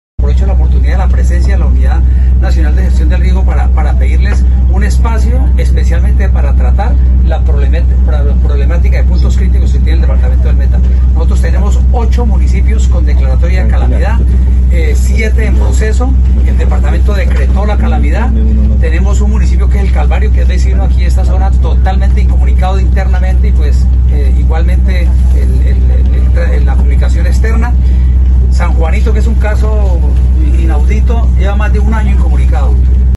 Audio: Hernando Martínez Aguilera, Director DIGERD del Meta